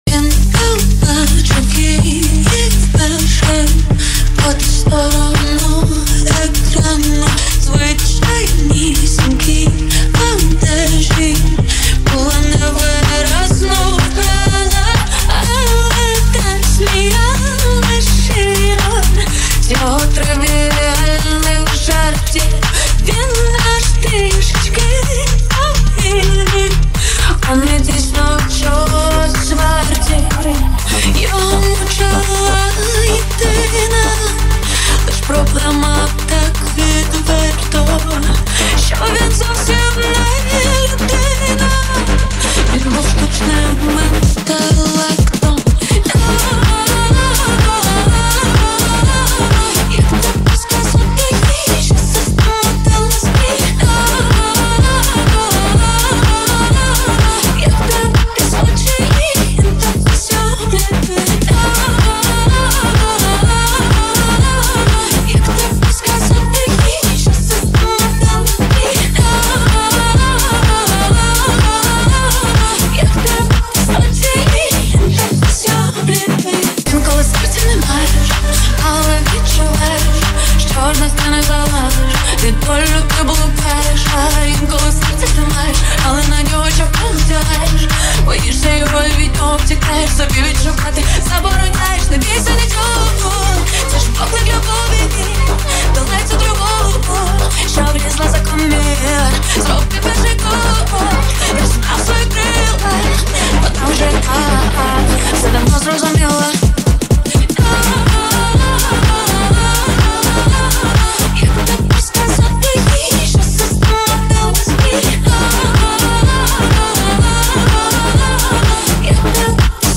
• Жанр: Pop, Electronic, Dance